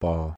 저성조 (Low)가시pospos
몽어 pos 발음